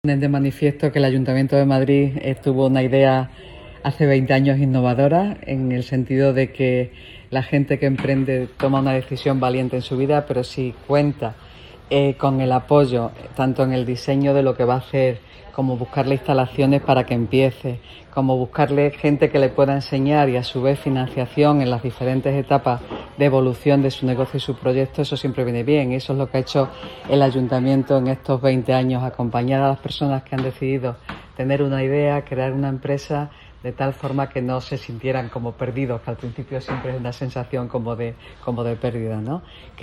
La delegada de Economía, Innovación y Hacienda, Engracia Hidalgo: